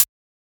Closed Hats
edm-hihat-12.wav